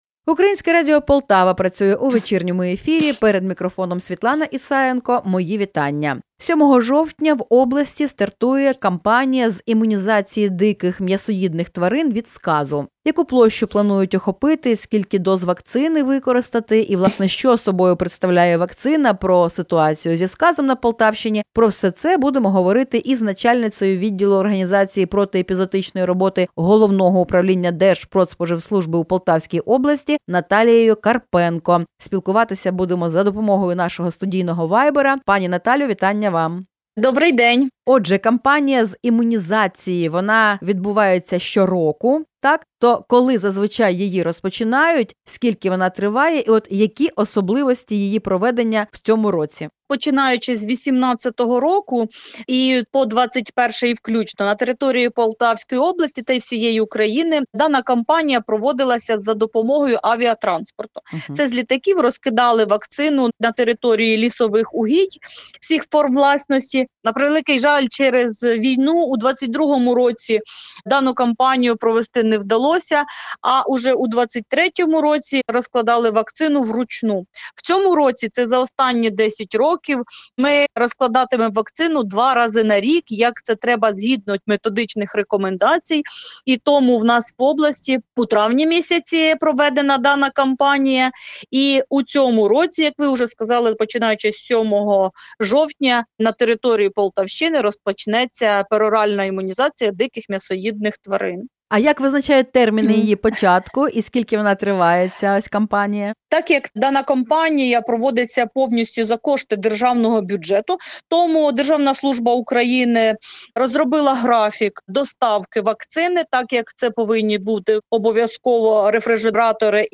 Особливості цьогорічної осінньої вакцинації диких тварин проти сказу на Полтавщині. Коментар журналістам